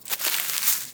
dig_3.wav